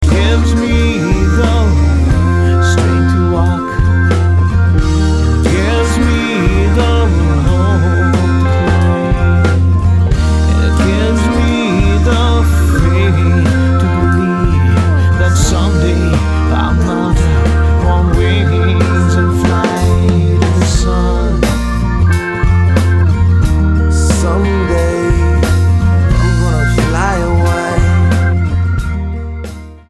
STYLE: Roots/Acoustic
An authentically loose and rootsy collection